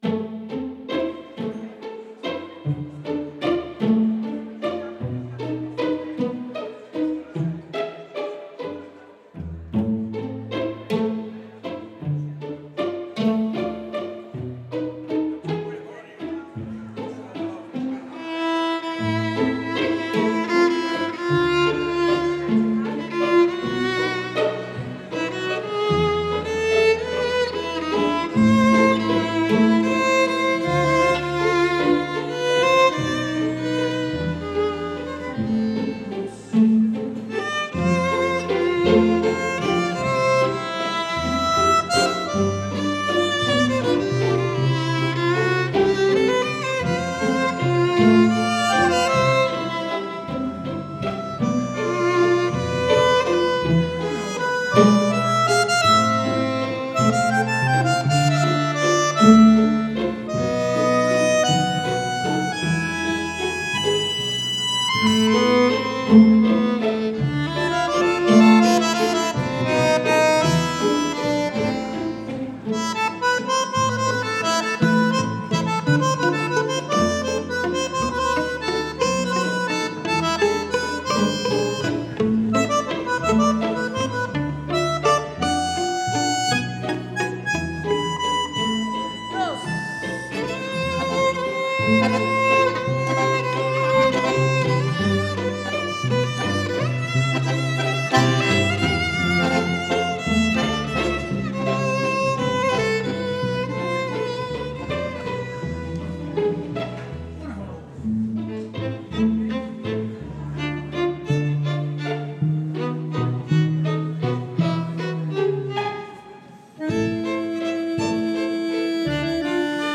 bandonéon
piano et vibrandoneon
violon
Contrebasse et Ukulélé basse
tiré de leur album live enregistré en novembre 2013.